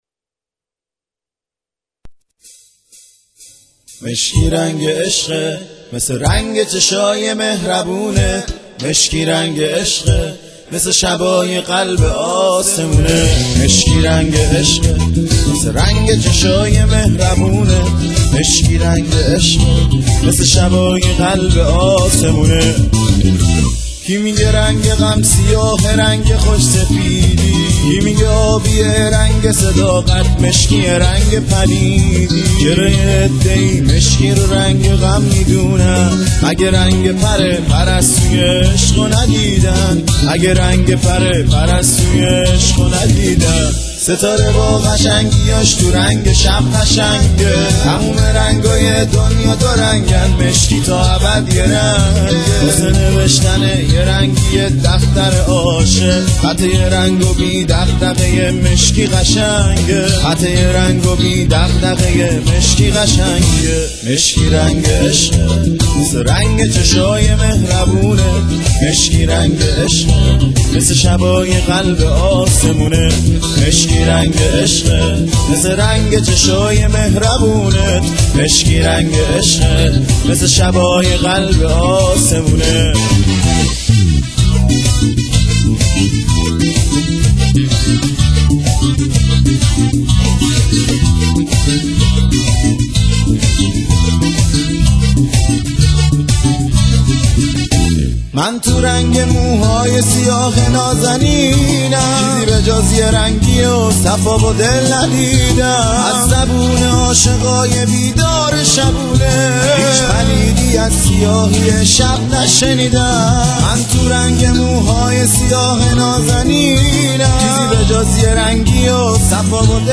خیلی شاد نیست